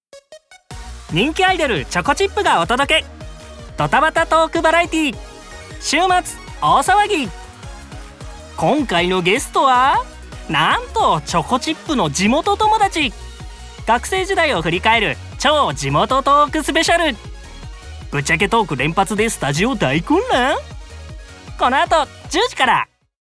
ボイスサンプル
2.番組告知(声色バージョン)(2025)